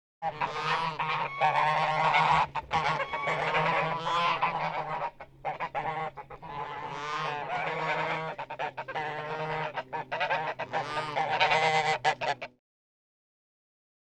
GEESE GOI02L.wav